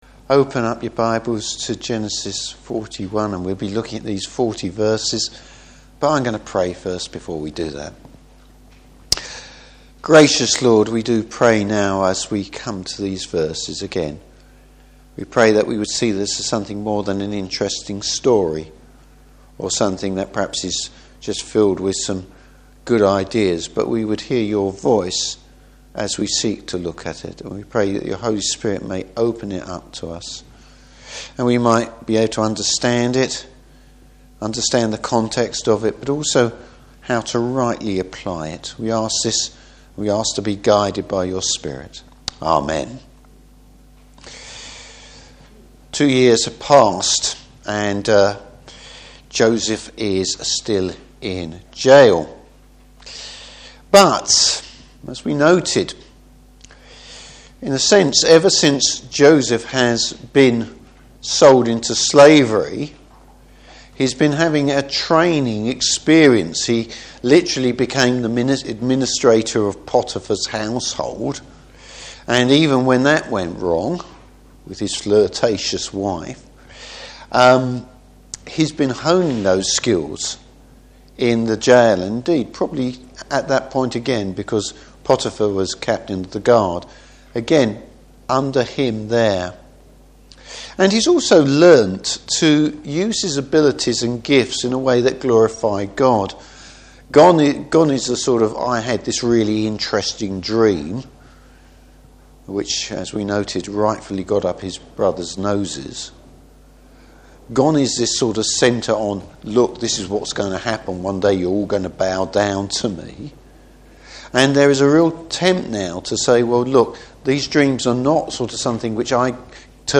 Service Type: Evening Service Joseph’s time has come!